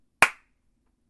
scar-mix-server/public/audio/clap@2/02.mp3 at a954a56f2fdb1bcf63ed17d2bf3f2a59bee7d1aa